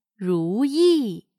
如意/Rúyì/Encontrar algo satisfactorio; ser gratificado. Un objeto en forma de “ese” (S), generalmente hecho de jade, usado como símbolo de buena suerte.